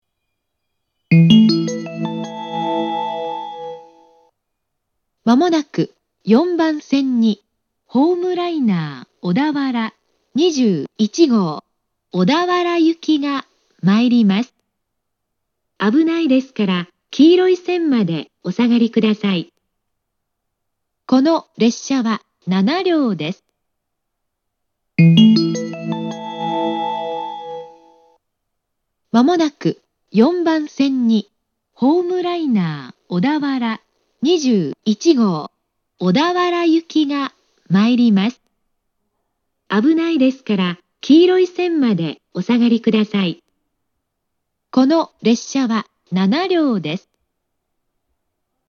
接近放送
HL小田原21号　小田原行（7両）の接近放送です。